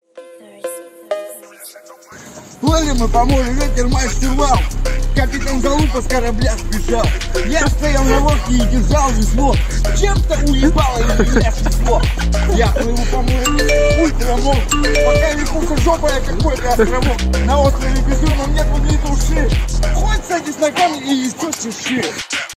ремиксы
рэп